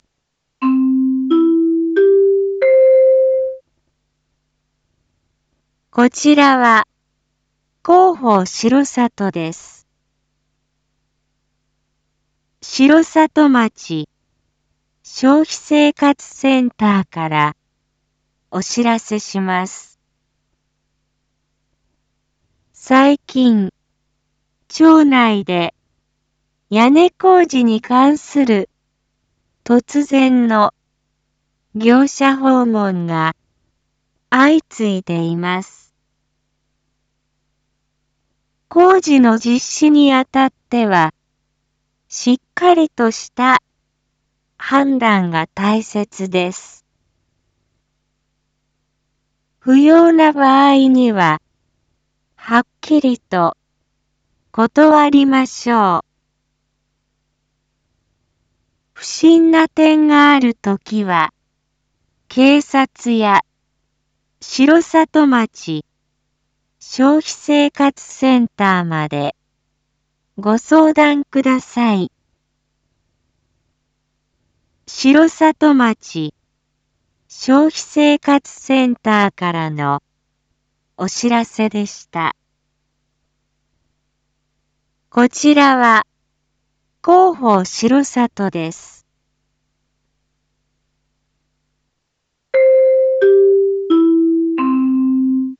一般放送情報
Back Home 一般放送情報 音声放送 再生 一般放送情報 登録日時：2024-09-02 19:01:37 タイトル：消費生活センターからの注意喚起① インフォメーション：こちらは、広報しろさとです。